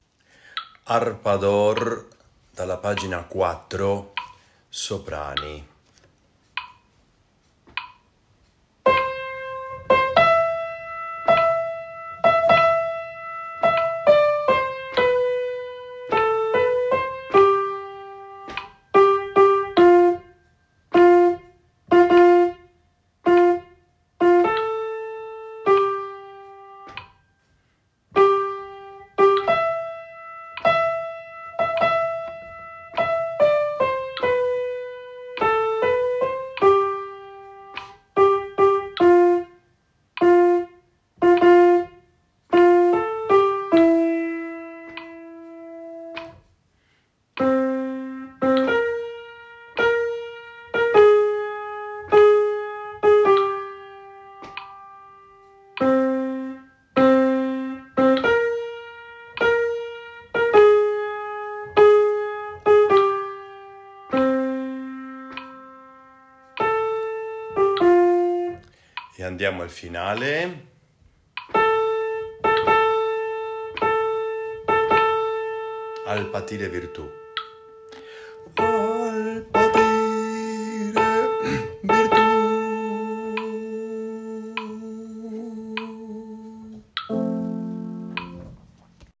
A.A. 24/25 Canto Corale